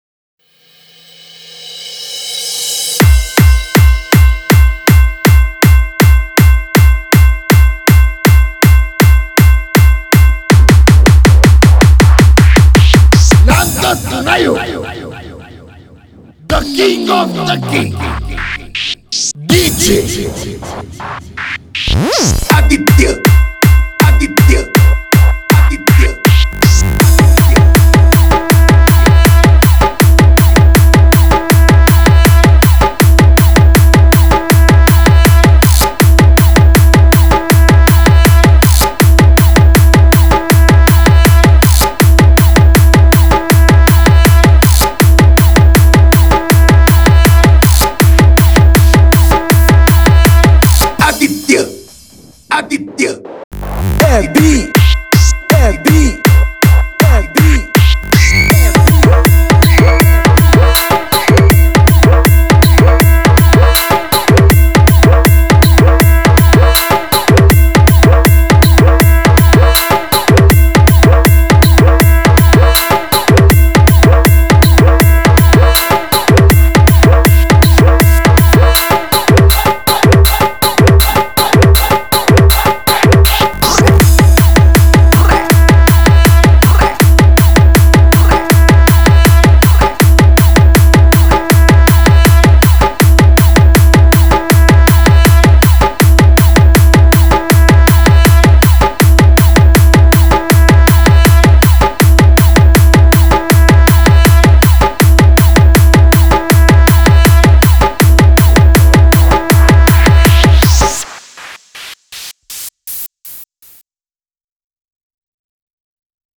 Dj Remixer
Competition Dj Remix